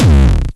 Интересная тема с хардовым киком.